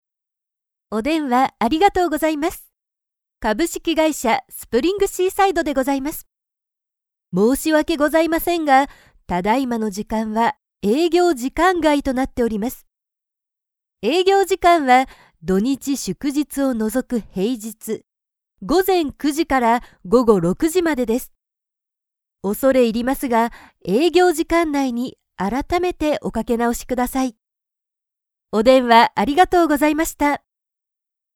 電話応答・営業時間外